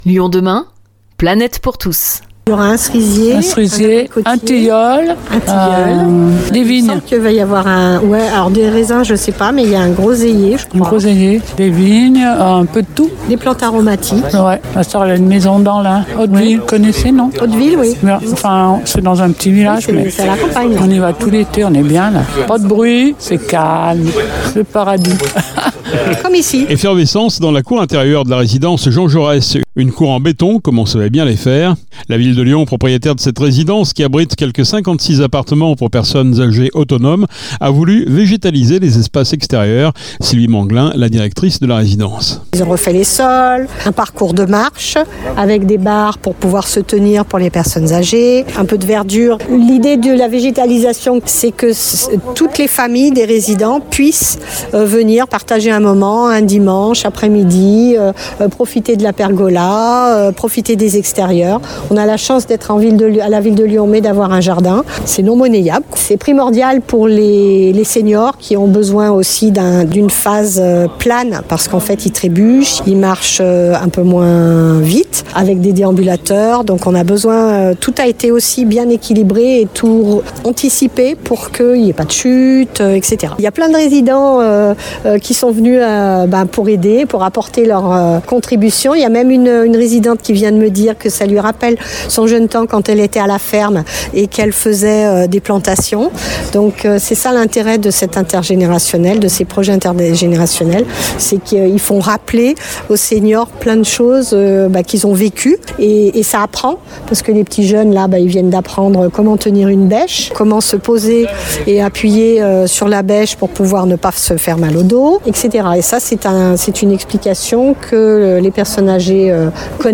Des résidentes qui papotent, des enfants qui manient la bêche, des professionnels du jardin qui insufflent leurs bons tuyaux…